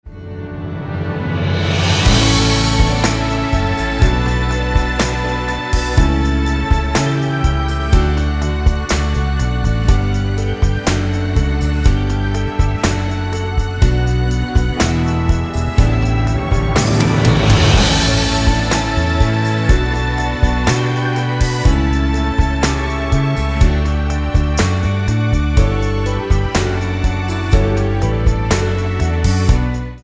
--> MP3 Demo abspielen...
Tonart:D-F ohne Chor